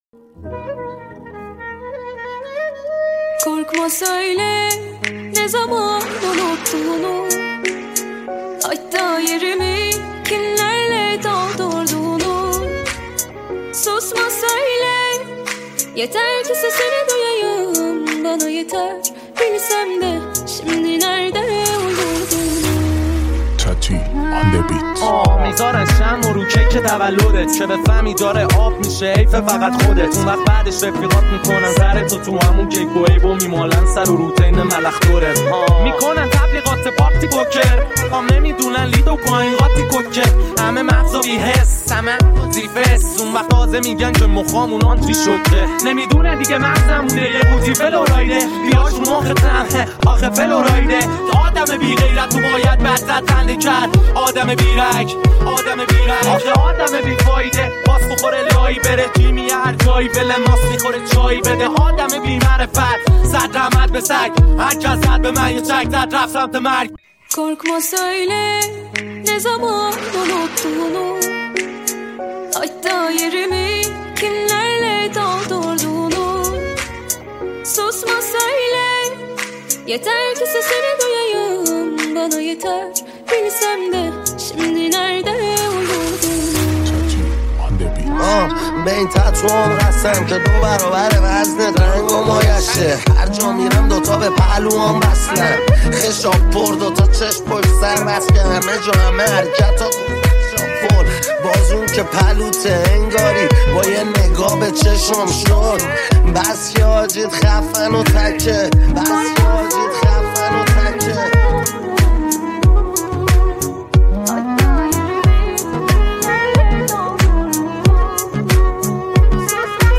ریمیکس رپ فارسی